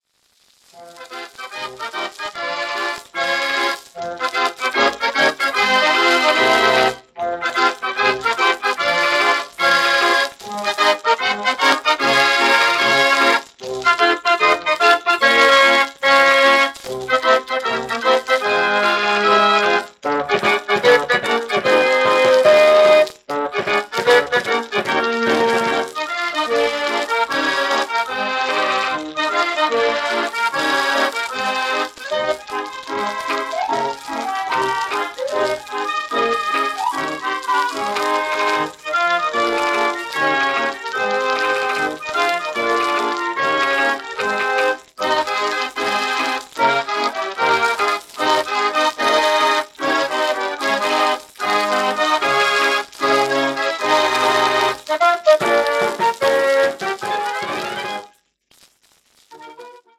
Uitvoerend orgel
Formaat 78 toerenplaat, 10 inch
Waltz